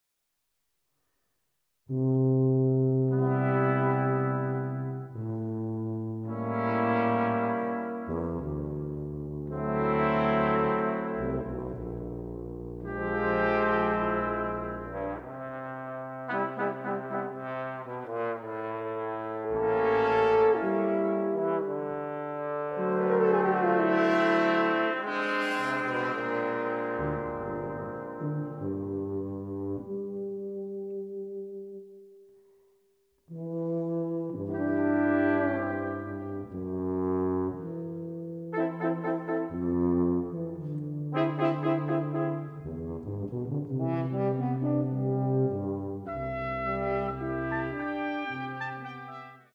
L’atmosfera è decisamente più serena nel secondo quadro:
Brass quintet